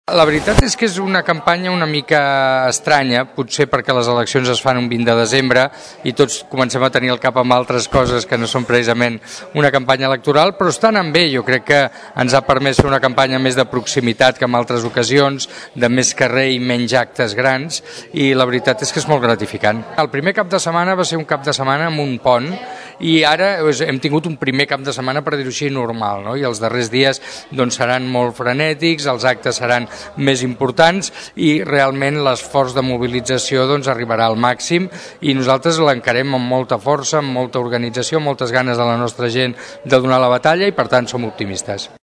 Prèviament, en declaracions en aquesta emissora, Iceta va demanar el vot pels socialistes assegurant que és l’única manera que hi hagi un canvi.